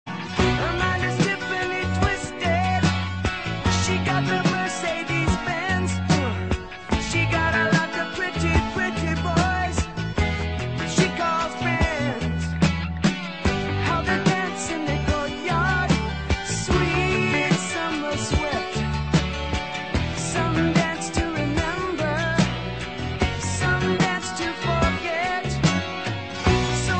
• Classical Ringtones